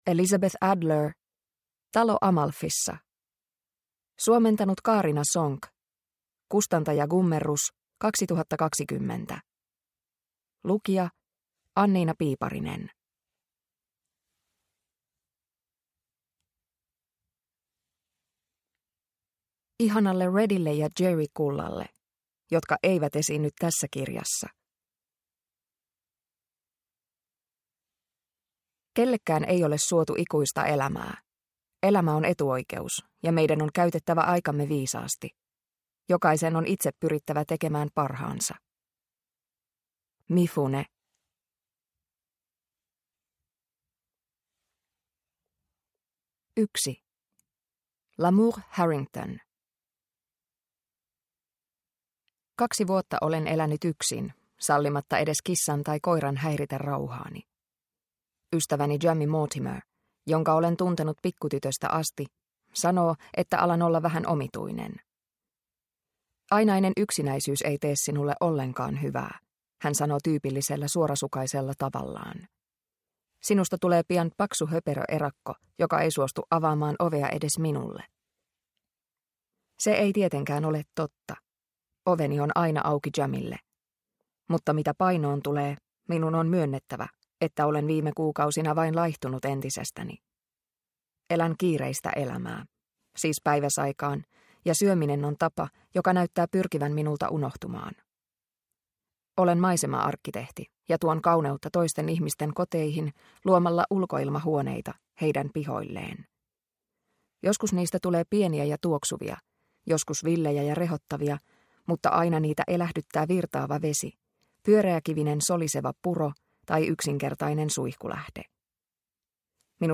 Talo Amalfissa – Ljudbok – Laddas ner